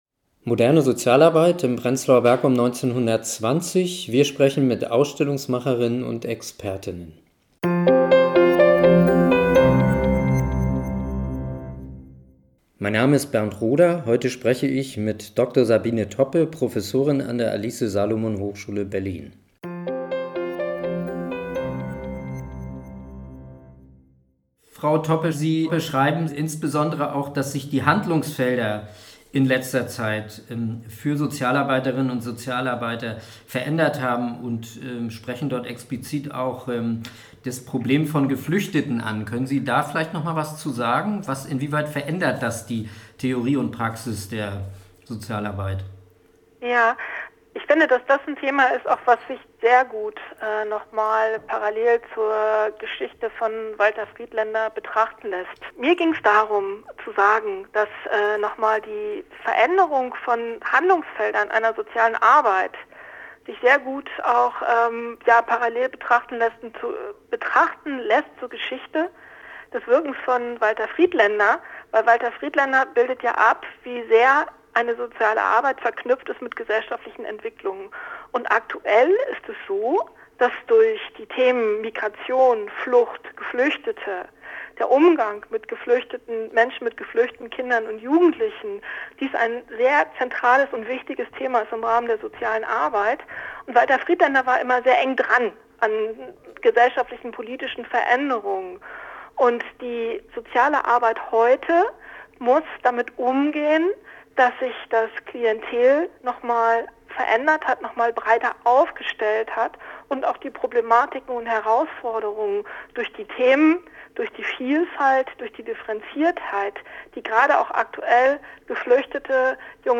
Interviews zum Thema: Aufbruch und Reformen – Pionierinnen und Pioniere der modernen Sozialarbeit in Prenzlauer Berg während der Weimarer Republik | Teil 2
Moderne Sozialarbeit in Prenzlauer Berg um 1920 – wir sprechen mit Ausstellungsmacher_innen und Expertinnen und Experten!